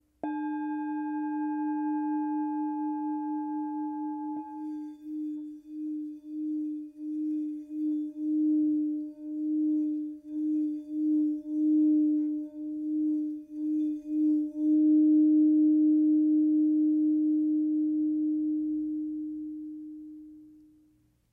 Tepaná tibetská mísa Dawa o hmotnosti 829 g. Mísa je včetně paličky s kůží!
tibetska_misa_s38.mp3